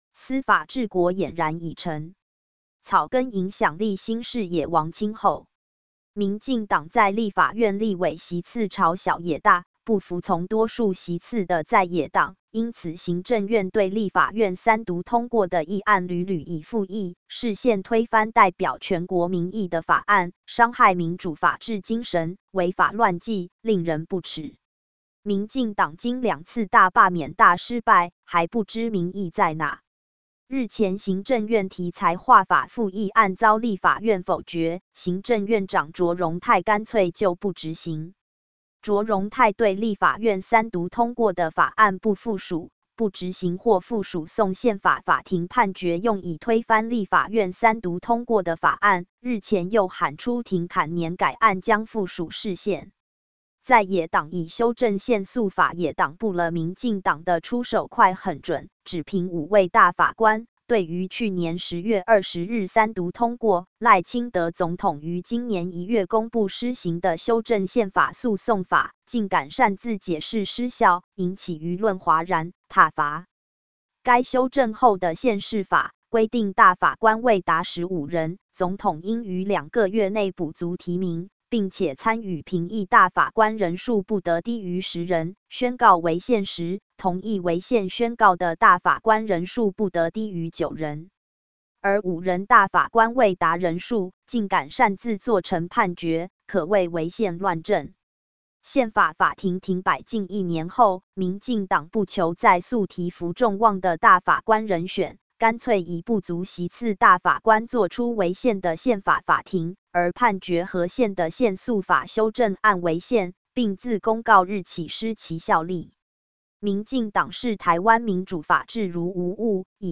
類型： Blues。